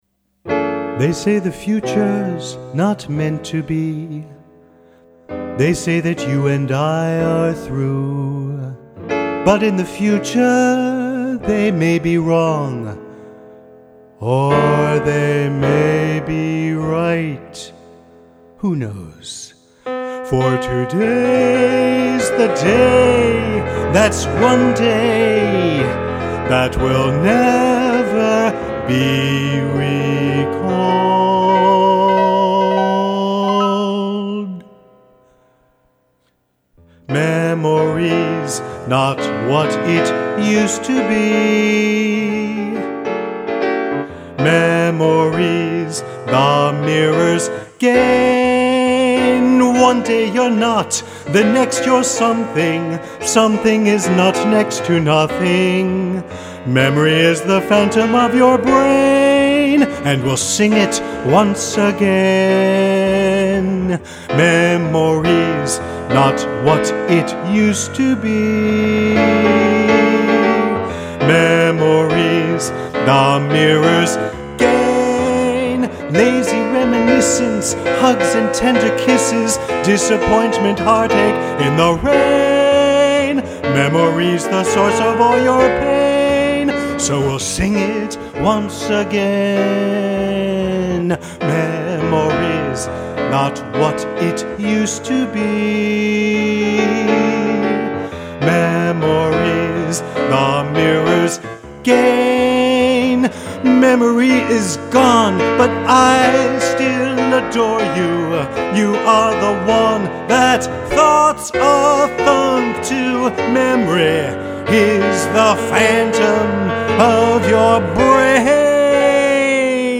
vocals
keyboard